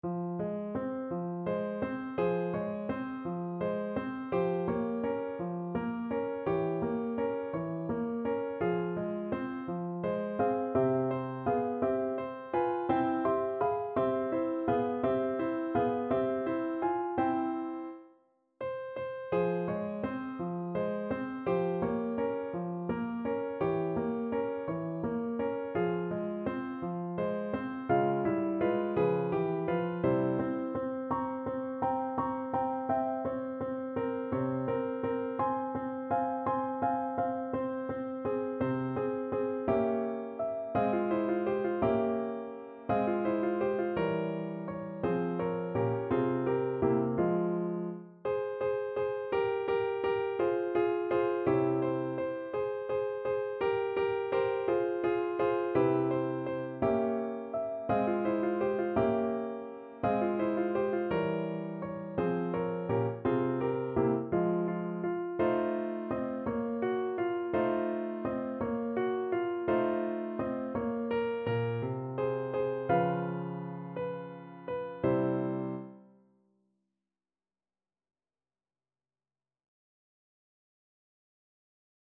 Piano version
No parts available for this pieces as it is for solo piano.
. = 56 Andante
6/8 (View more 6/8 Music)
Piano  (View more Intermediate Piano Music)
Classical (View more Classical Piano Music)